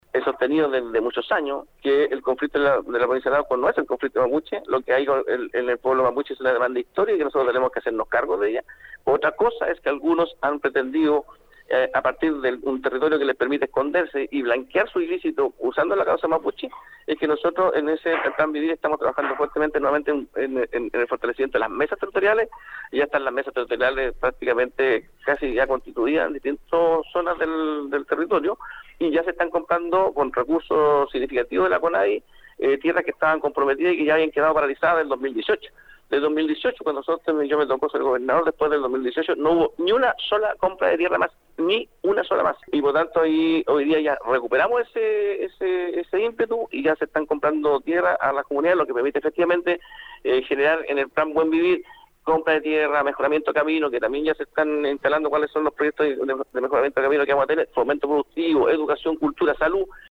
La autoridad provincial, en entrevista con Nuestra Pauta, destacó tanto el trabajo coordinado entre las policías y el Ministerio Público en la persecución de delitos como el vinculo con municipios y organizaciones sociales en materias de seguridad pública.